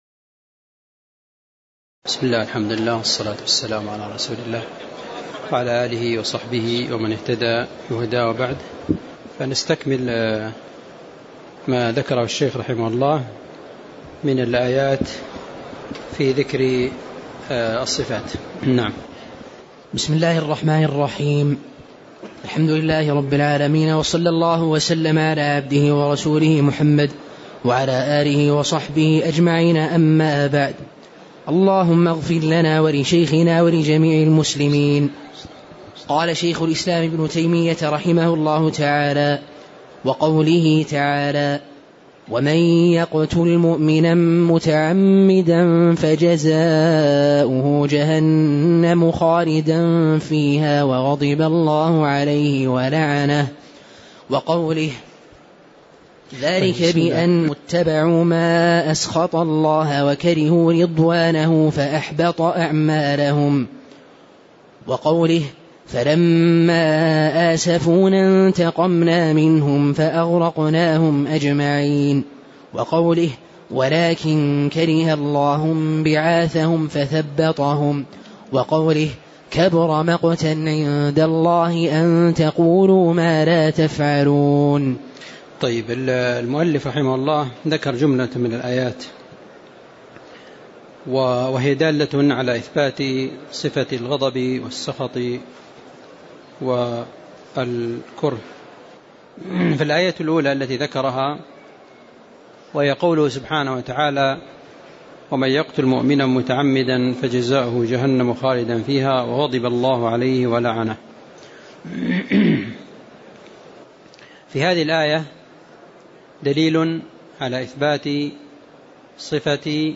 تاريخ النشر ١١ شوال ١٤٣٨ هـ المكان: المسجد النبوي الشيخ